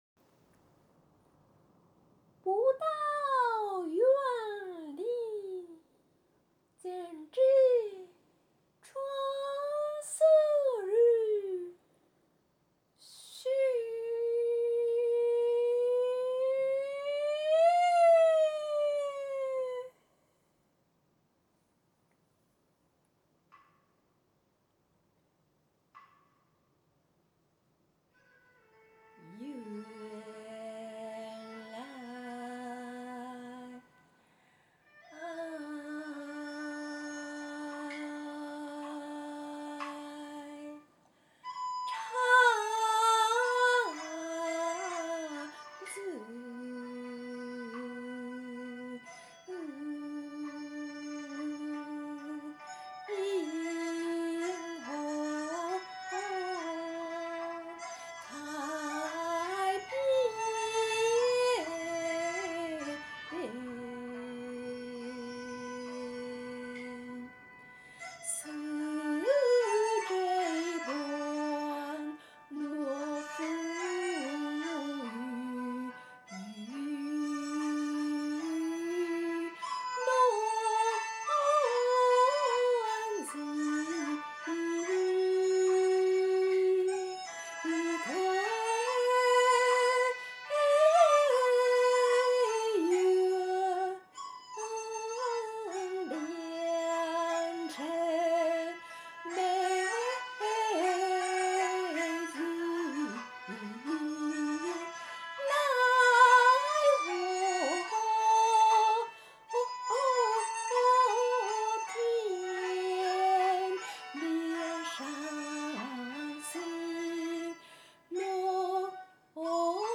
歌唱コンテスト結果発表
・昆曲唱得太好听了。
・古香古色的感觉，外国人可以唱出这种韵味，很难得。
・音色非常好，让人立马投入到其中
・我虽然不懂昆曲，但是听着很有韵味，戏腔很足。感觉您是一位     资深票友。很厉害